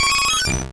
Cri d'Évoli dans Pokémon Rouge et Bleu.